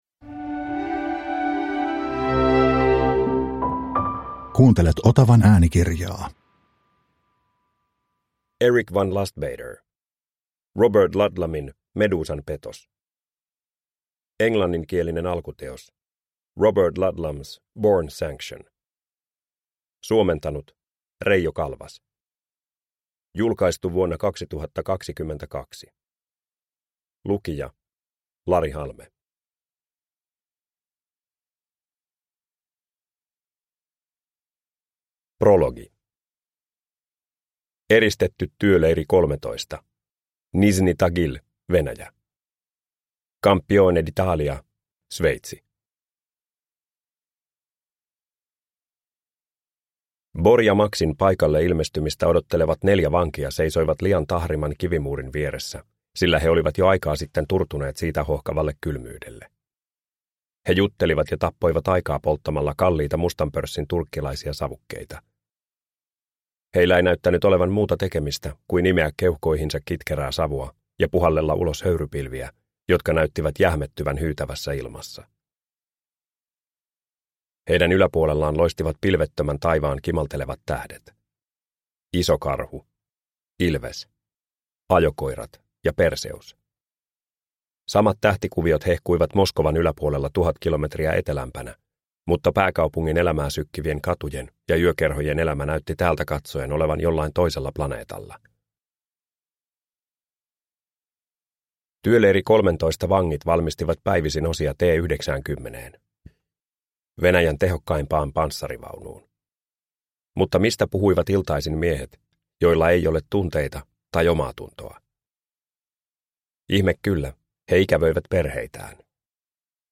Robert Ludlumin Medusan Petos – Ljudbok – Laddas ner